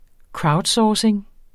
Udtale [ ˈkɹɑwdˈsɒːseŋ ]